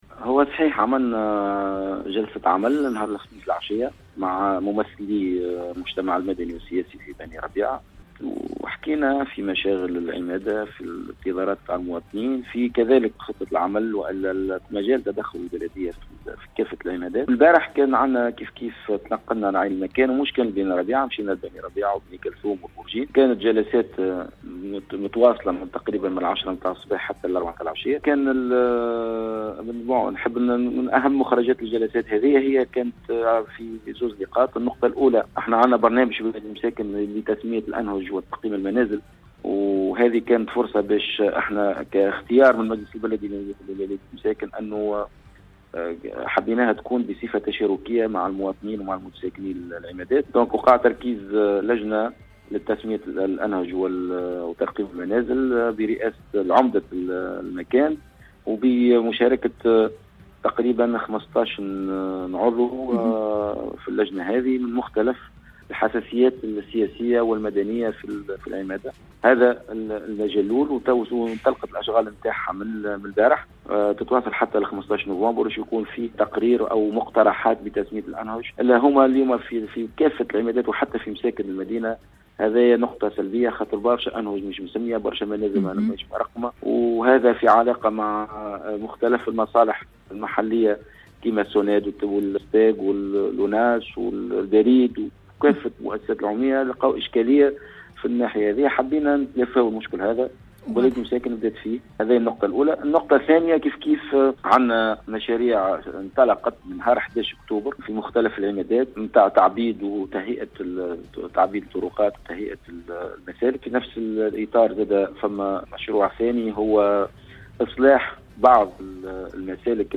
حيث أكد لنا رئيس بلدية مساكن محمد علية في تصريح ببرنامج “حديث الـRM ” أنه تم تركيز لجنة لتسمية الأنهج وترقيم المنازل برئاسة عُمَد مختلف المناطق البلديّة وبمشاركة مختلف الحساسيات السياسيّة والمدنيّة في كل عمادة حيث انطلقت أشغالها يوم الإثنين 15 أكتوبر 2018 وتتواصل إلى يوم 15 نوفمبر حتى تتمّ تسوية هذا الإشكال الذي يتداخل مع مختلف المصالح العموميّة كالبريد والصوناد والأوناس وحتى المصالح البلديّة والخدماتيّة .
*تصريح
زيارة-ميدانيّة-محمد-علية-17-أكتوبر.mp3